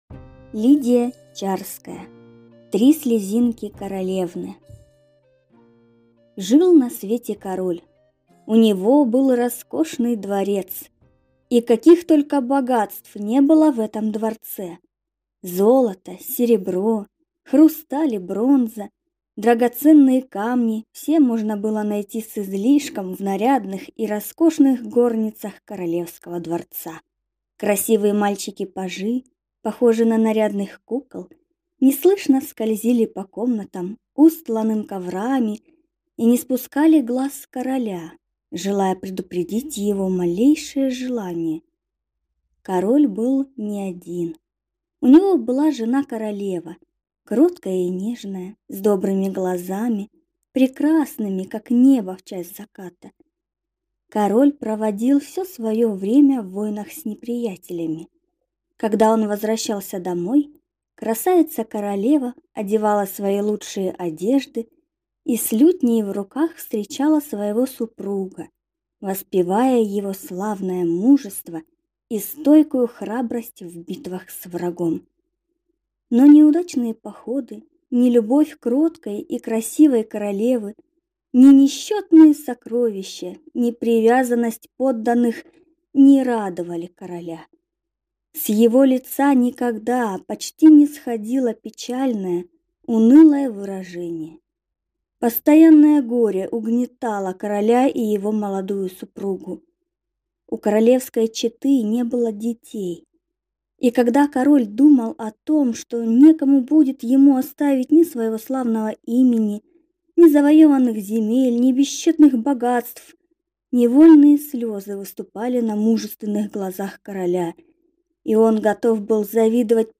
Аудиокнига Три слезинки королевны | Библиотека аудиокниг